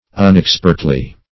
unexpertly - definition of unexpertly - synonyms, pronunciation, spelling from Free Dictionary Search Result for " unexpertly" : The Collaborative International Dictionary of English v.0.48: Unexpertly \Un`ex*pert"ly\, adv.